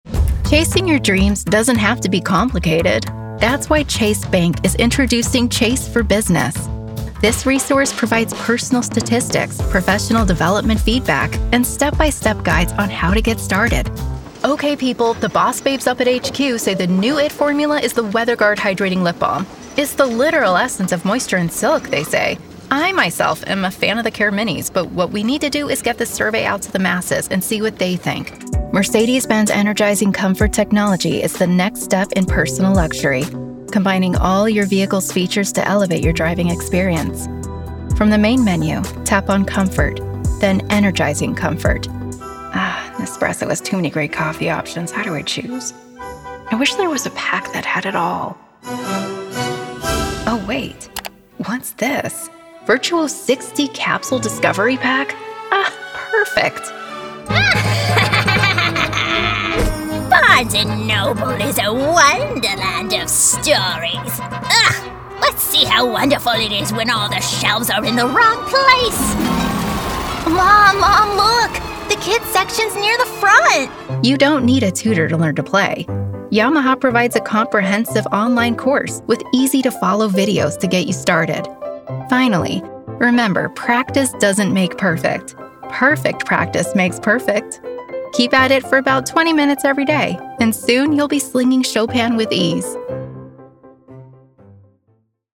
Voiceover Artist - Heartfelt Storytelling
Corporate Narration Demo
Neutral English
I use a Sennhesier 416 microphone, a Scarlett 2i2 audio interface, and I record into Logic Pro X.